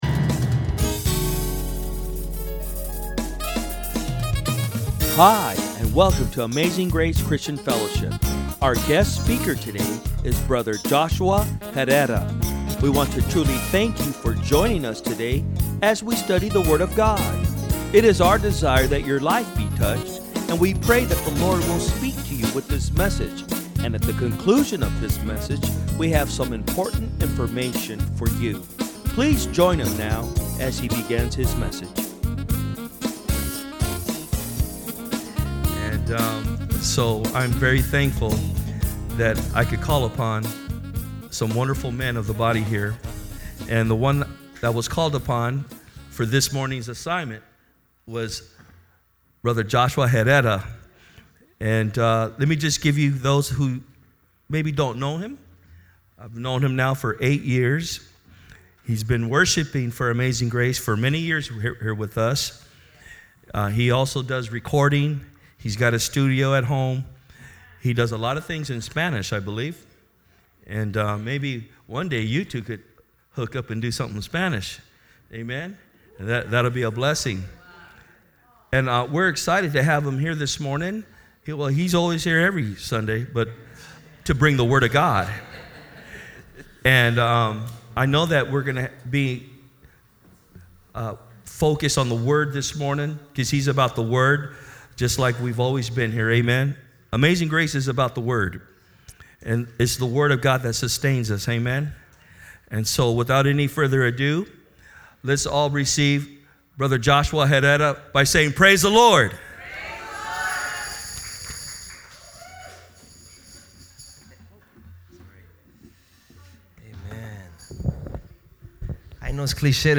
Message
From Service: "Sunday Am"